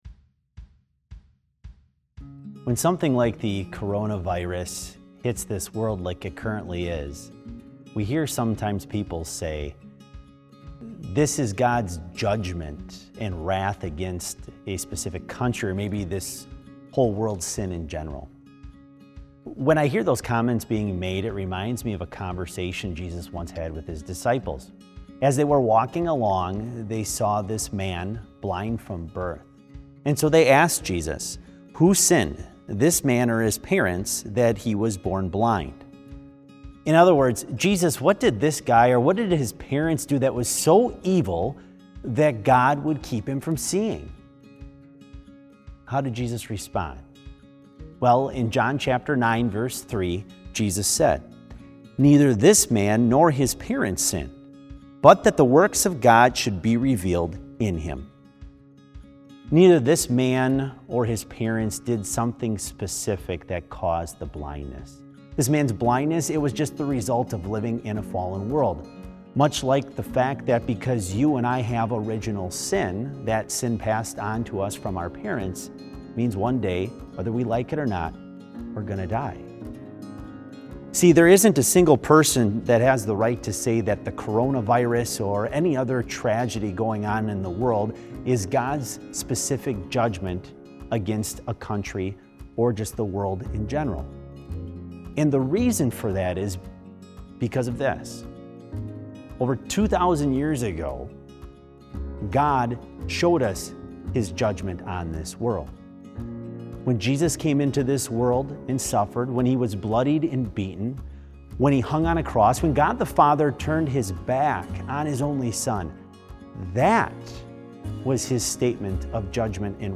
Complete service audio for BLC Devotion - March 23, 2020